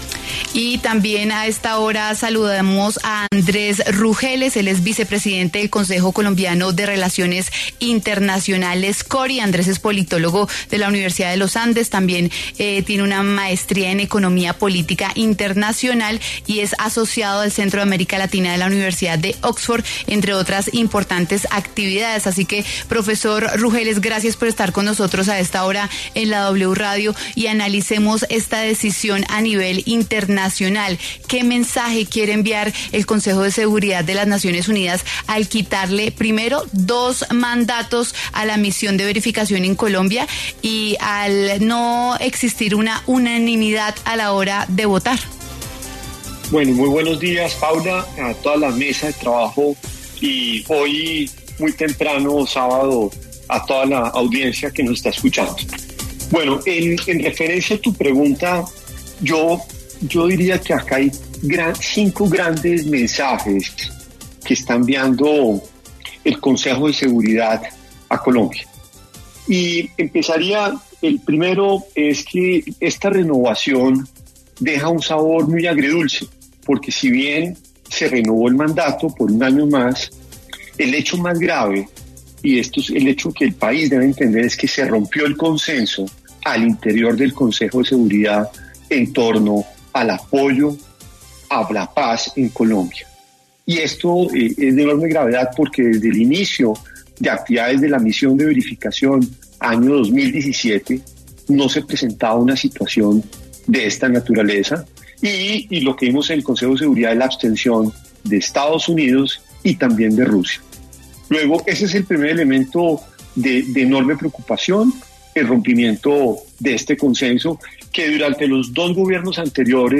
habló en W Fin De Semana sobre la renovación que hizo ONU de la Misión de Verificación en Colombia